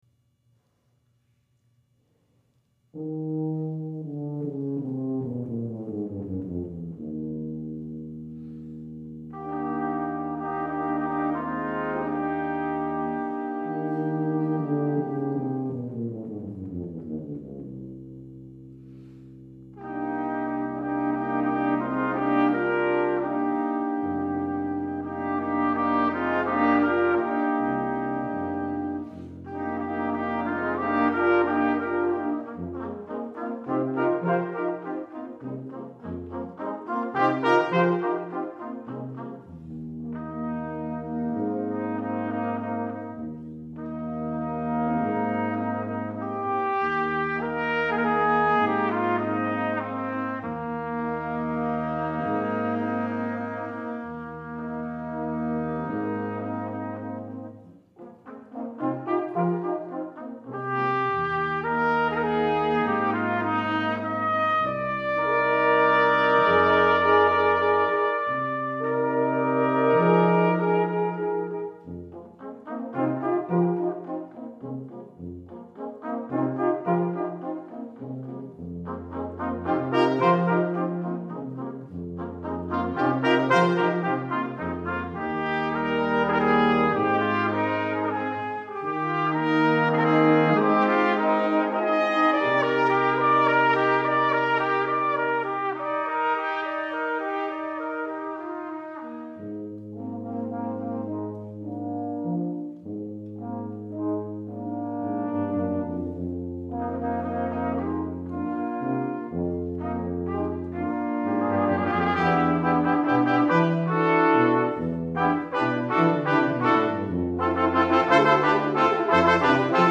for Brass Quintet (2000)